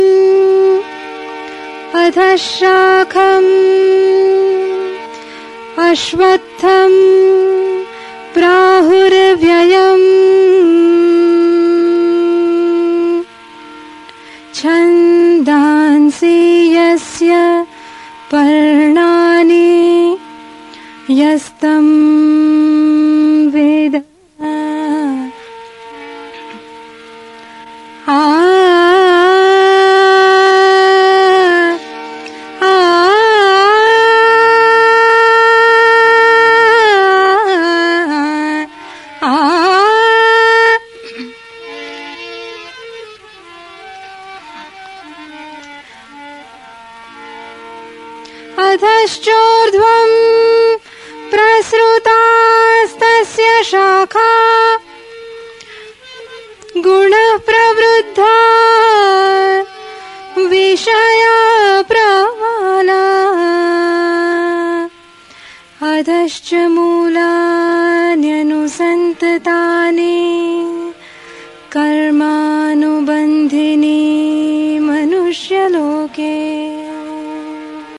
Early years recital